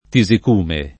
[ ti @ ik 2 me ]